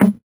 CS_VocoBitA_Hit-12.wav